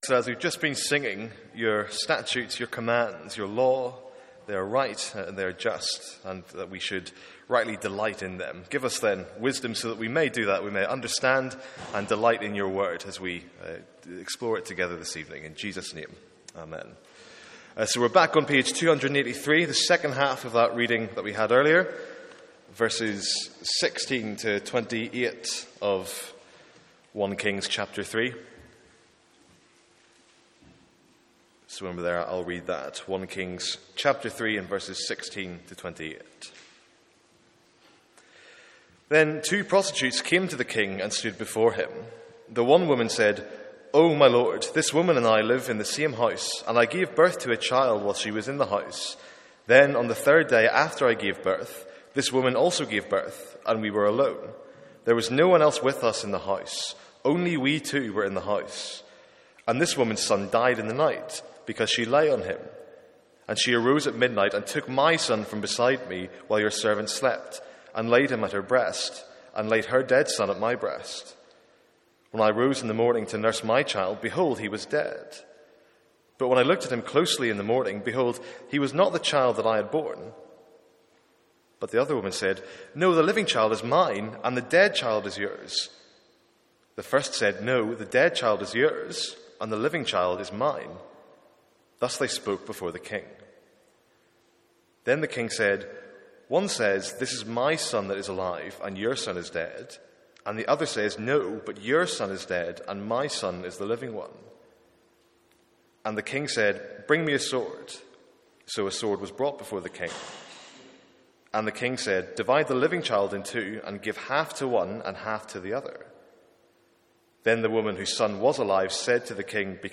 Sermons | St Andrews Free Church
From our evening series in 1 Kings